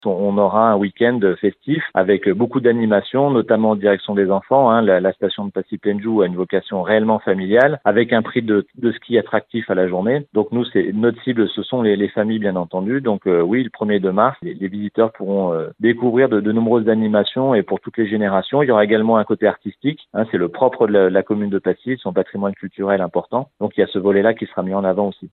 Raphael Castera est le maire de Passy :